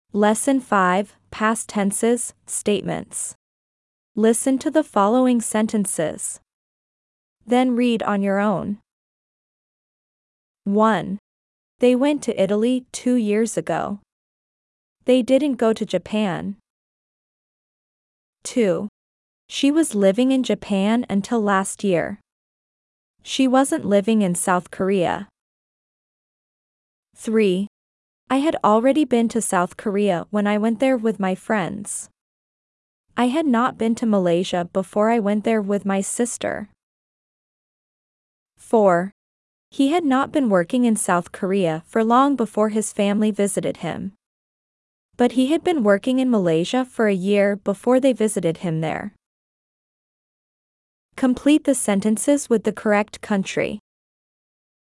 As you progress through the exercises and listen to the example statements and dialogs, you’ll not only strengthen your grasp on key English tenses and sentence structures but also enrich your vocabulary with the English names of countries from around the globe.